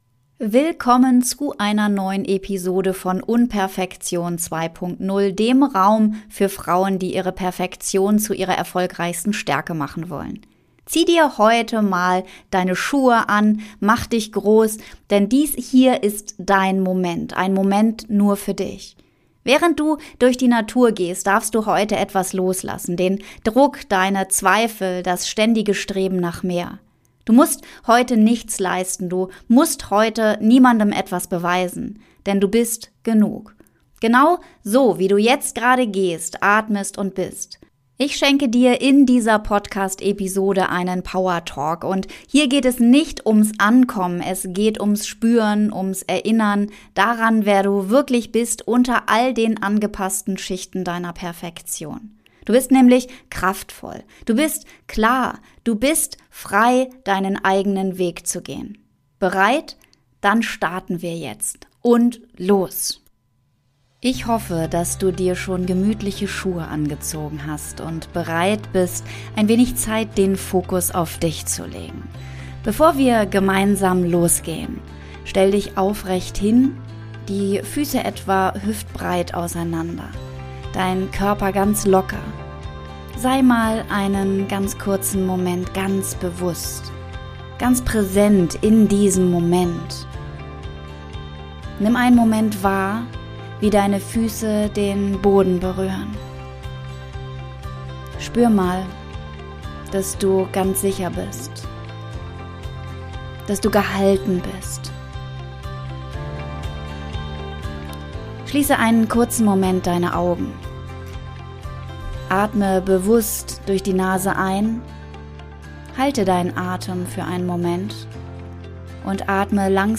064 - Gehmeditation: Du bist gut genug ~ UNperfektion 2.0 - so perfekt. UNperfekt. Podcast